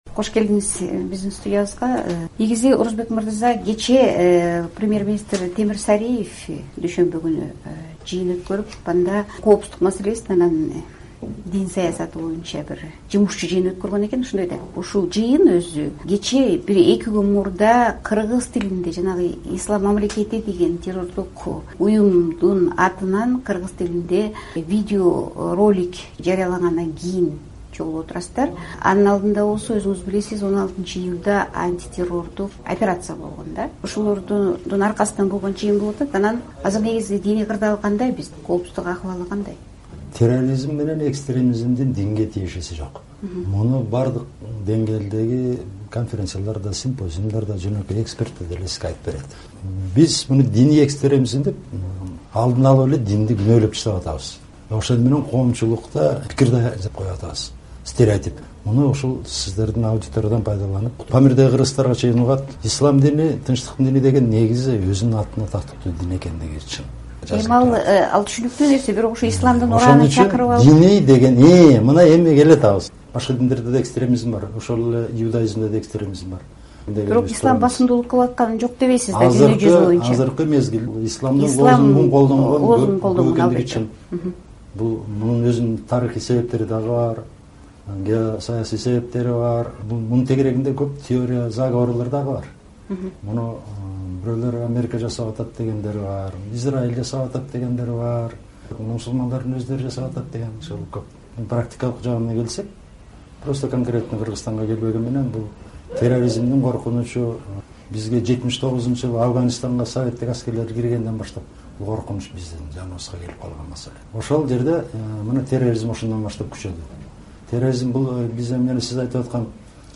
Дин иштери боюнча мамлекеттик комиссиянын жетекчиси Орозбек Молдалиев "Азаттыктын" 2х1 форматындагы видео-радио маегинде Кыргызстандагы диний радикализм коркунучтары, динди саясатка аралаштыруудагы тобокелчиликтер, исламдын кыргыз моделин түзүү аракеттери тууралуу пикирлери менен бөлүштү.